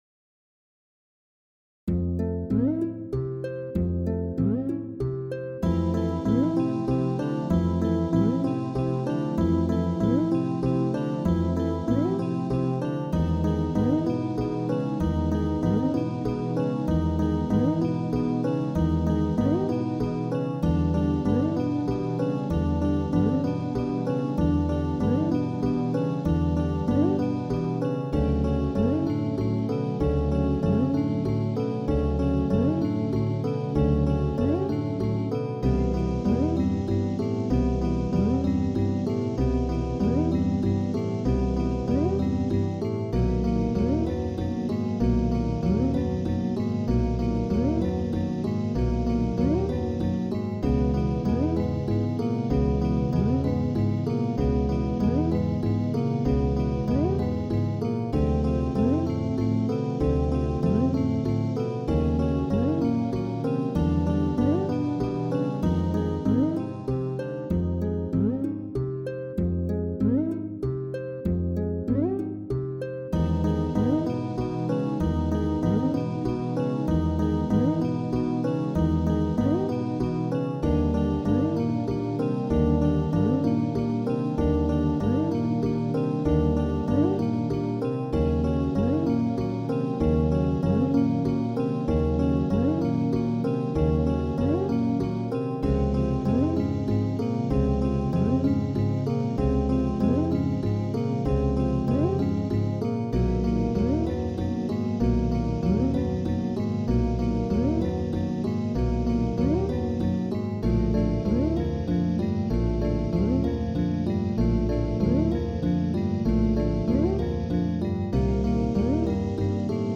ピッチベンドいじくったクイーカは泡のつもり。無駄に半音下げたパターンも入れ込んでおり、実質4ループ。